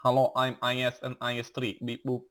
Beep boop